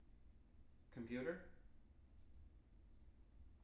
wake-word
tng-computer-100.wav